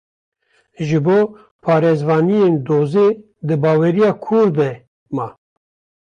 Pronunciado como (IPA)
/kʰuːɾ/